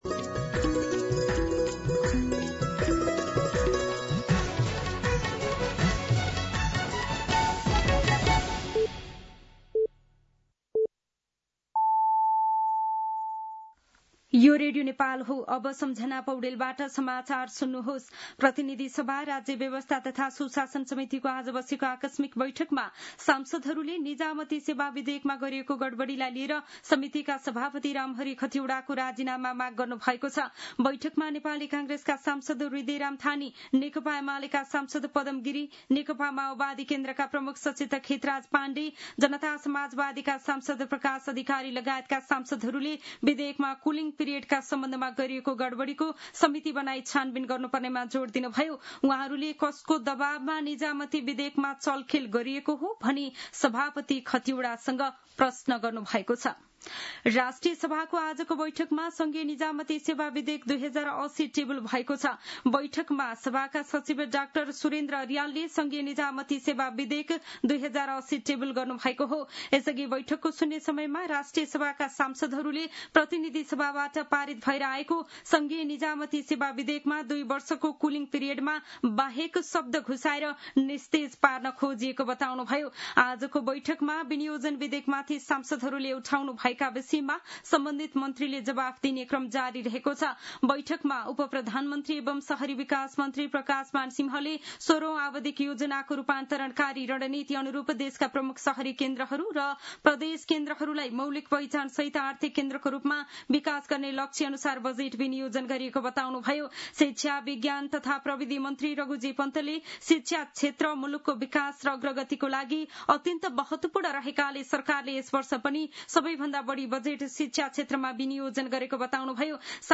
दिउँसो ४ बजेको नेपाली समाचार : १८ असार , २०८२